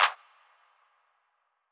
Tm88 Evil Clap.wav